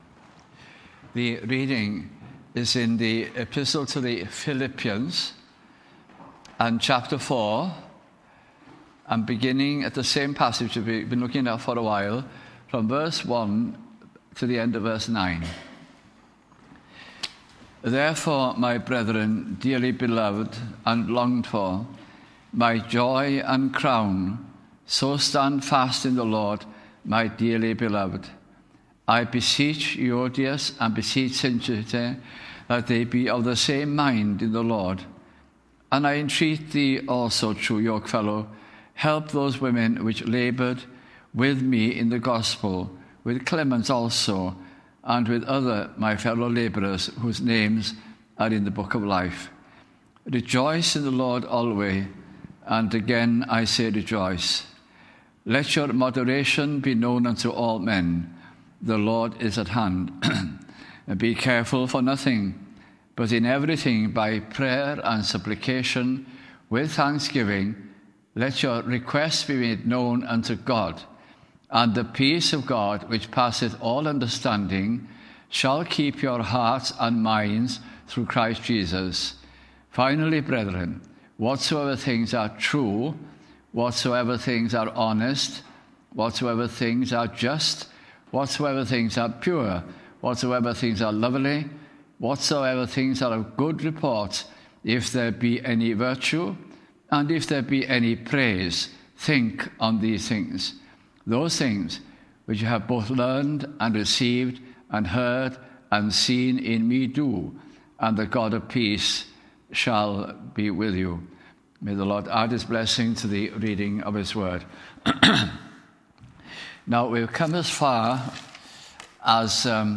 » Philippians » Bible Study Series 2008 - 2009 » at Tabernacle Cardiff in the Heath Citizens Hall and then at Pen-y-wain Road, Roath